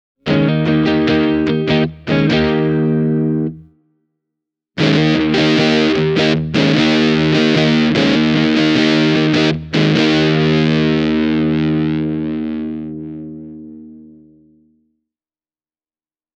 This Halla Custom guitar features a built-in passive fuzz circuit, which can be activated using a push/pull-switch hidden in the neck pickup’s tone control.
Halla’s built-in passive fuzz is a fun little addition that’s more than a gadget, especially when you’re playing with a single channel amp:
halla-custom-sg-e28093-built-in-passive-fuzz.mp3